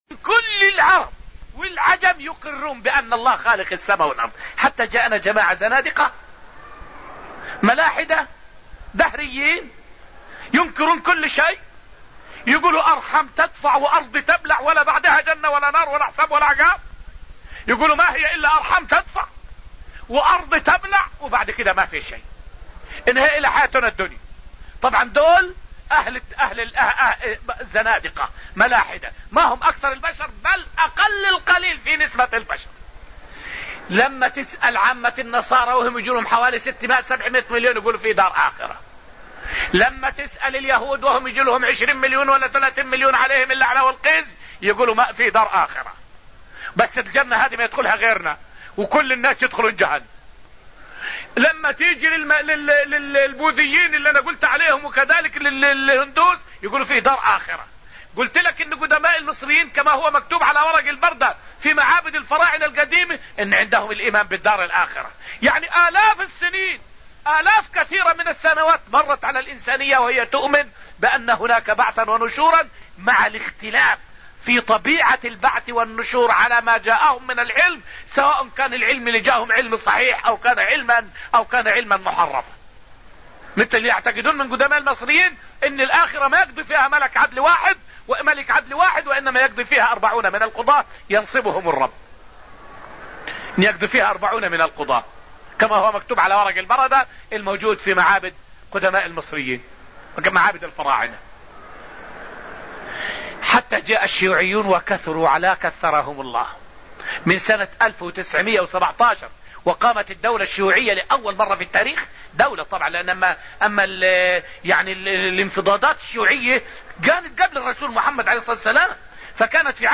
فائدة من الدرس العاشر من دروس تفسير سورة النجم والتي ألقيت في المسجد النبوي الشريف حول الشك في الله ورسالة نبيه صلى الله عليه وسلم ارتداد عن دينه.